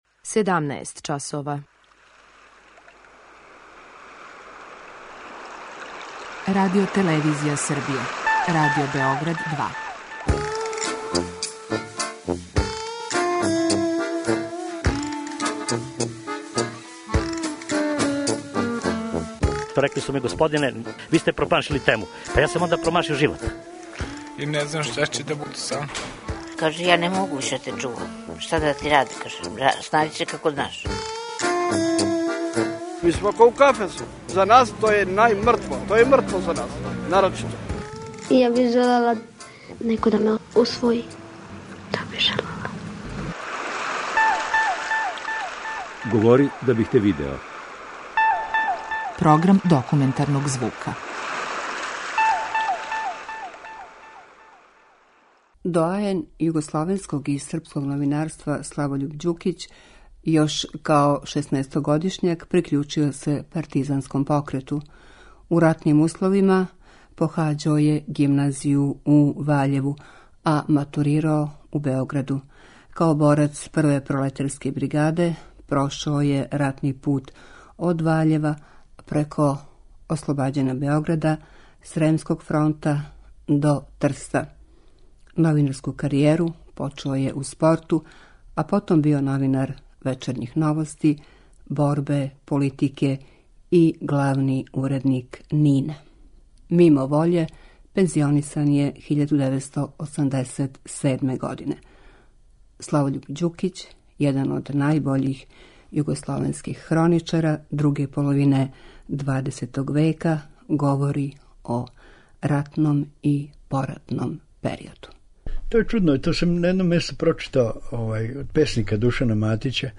Документарни програм
Славољуб Ђукић, један од најбољих југословенских хроничара друге половине XX века, говори о ратном и поратном периоду.